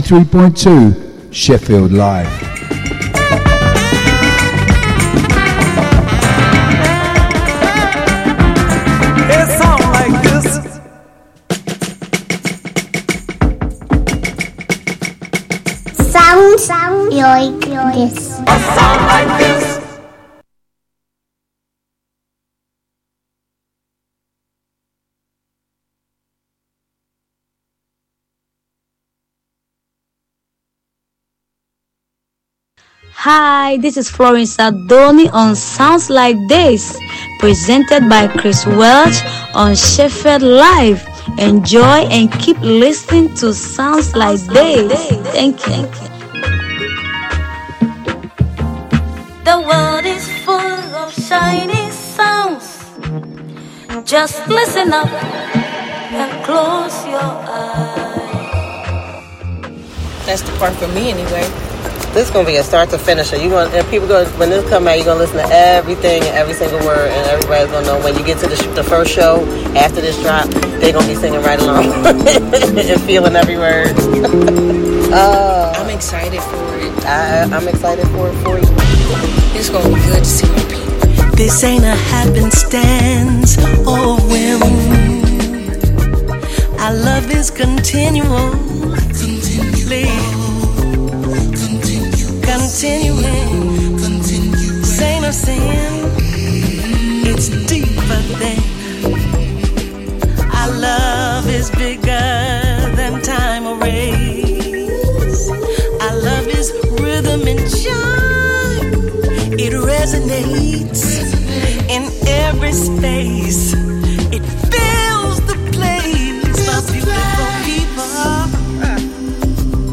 Roastin some leftfield dance type music!